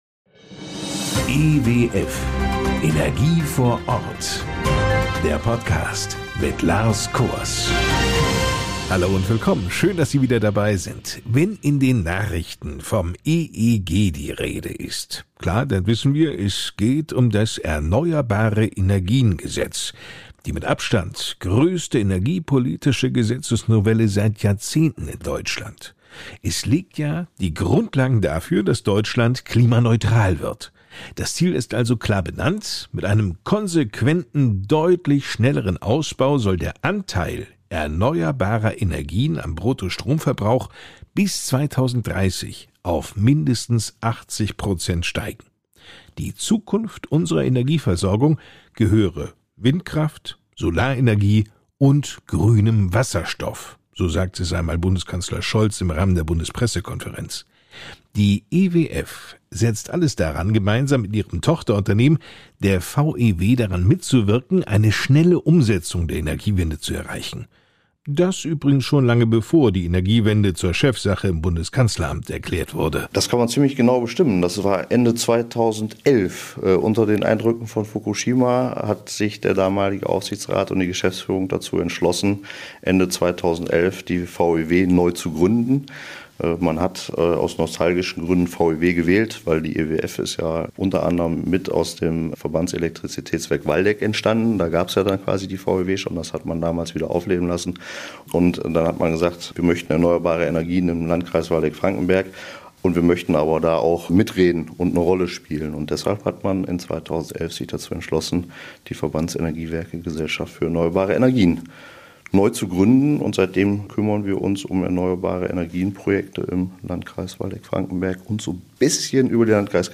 In diesem Podcast erwarten Sie informative Interviews mit Experten aus der Branche, die Ihnen Einblicke in innovative Technologien und zukunftsweisende Konzepte geben.